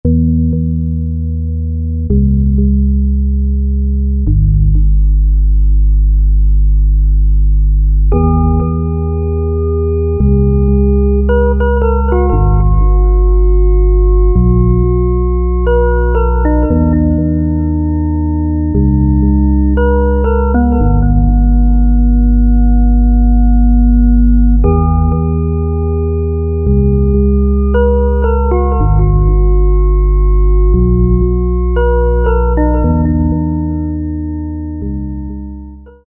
178 Dream Organ
The classic Robert Miles' "Children" sound.
178 Dream Organ.mp3